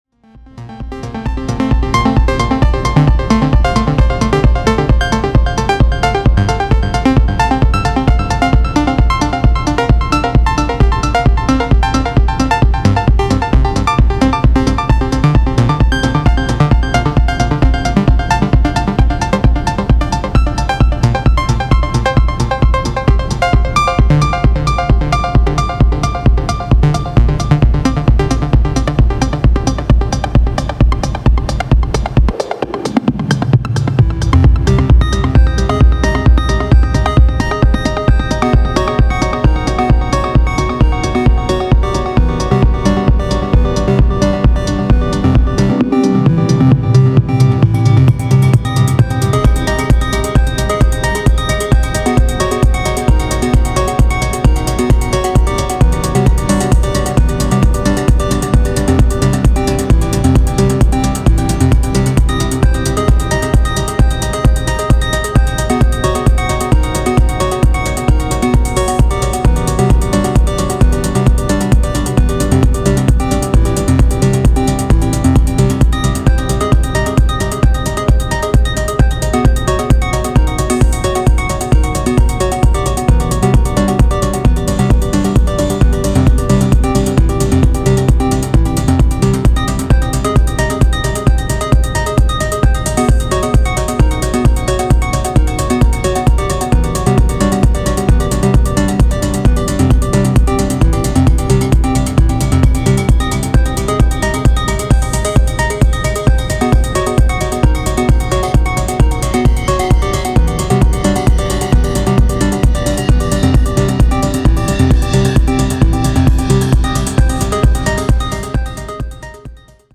シンセの音色が深い時間の脳髄に響き過ぎそうな